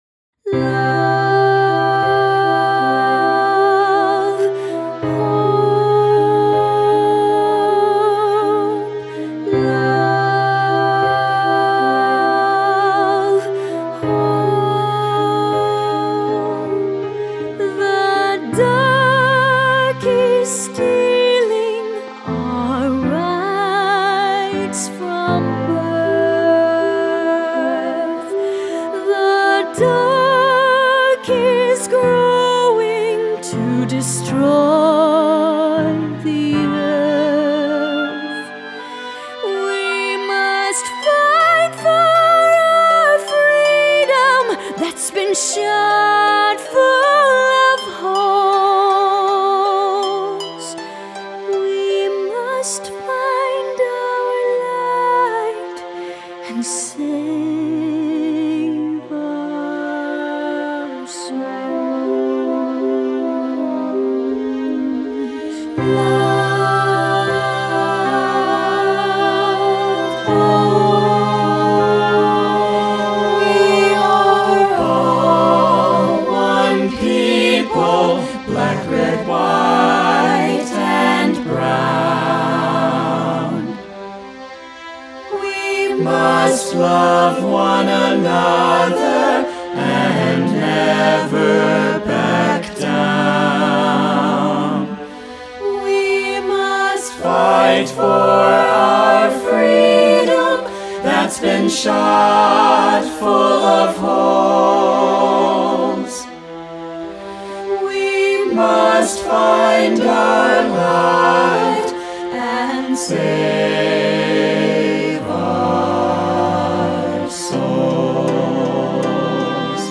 01-Save-Our-Souls-NO-SPOKEN.mp3